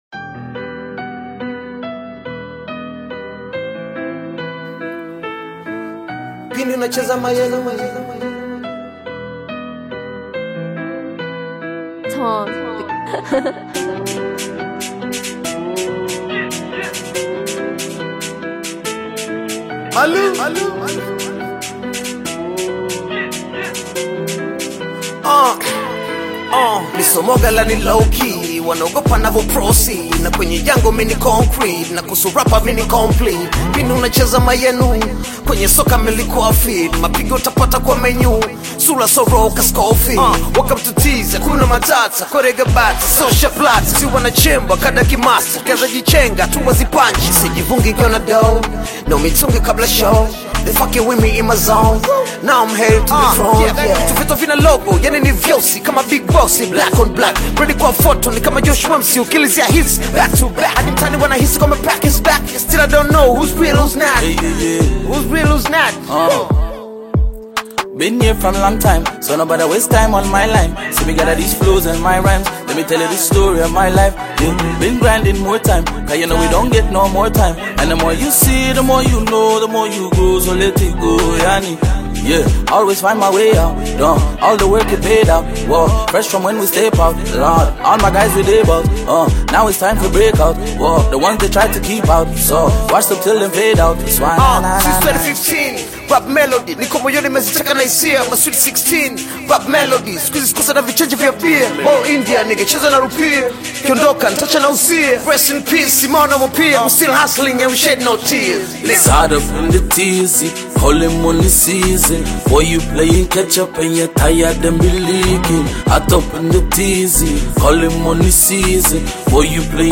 Tanzanian hip-hop